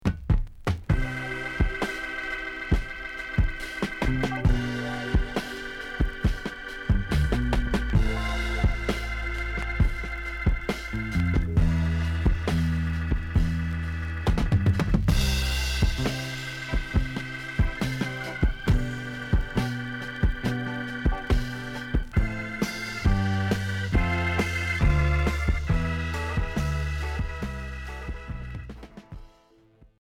Progressif révolutionnaire Unique 45t retour à l'accueil